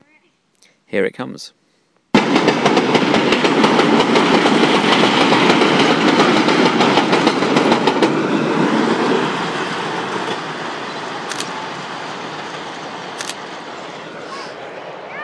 Sound of the gravity train